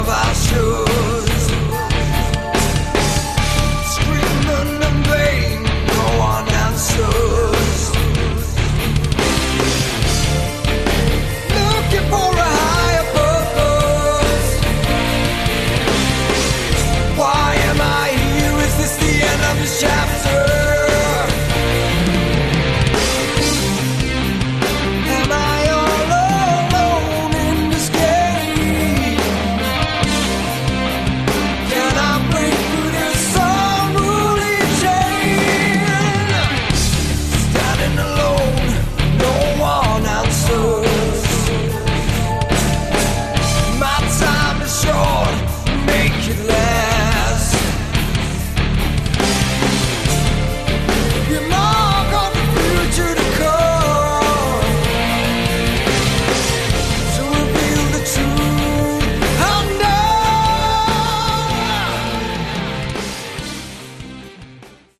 Category: Melodic Prog Rock
electric and acoustic guitars
keyboards, backing vocals
drums, backing vocals